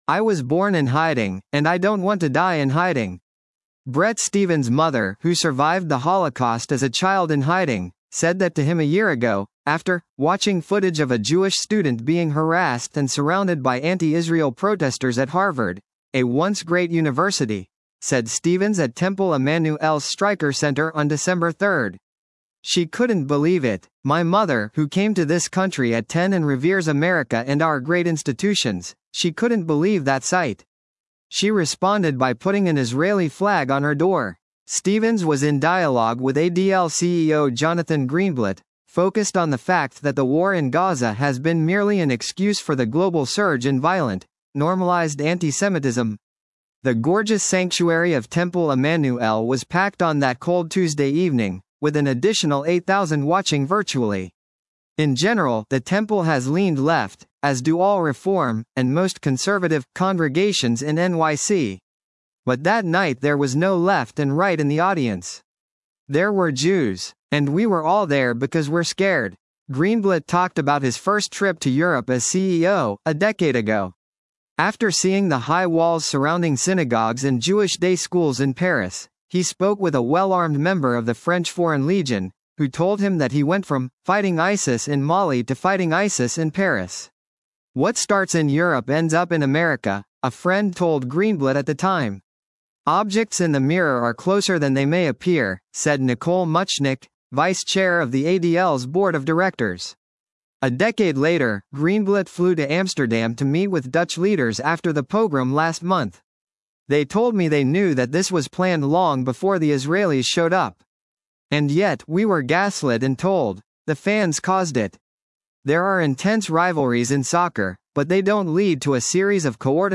Bret Stephens was in dialogue with ADL CEO Jonathan Greenblatt, focused on the fact that the war in Gaza has been merely an excuse for the global surge in violent, normalized antisemitism.
The gorgeous sanctuary of Temple Emanu-El was packed on that cold Tuesday evening, with an additional 8,000 watching virtually.